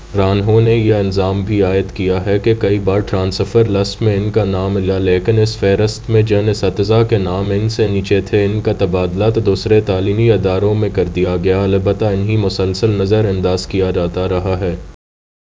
Spoofed_TTS/Speaker_11/105.wav · CSALT/deepfake_detection_dataset_urdu at main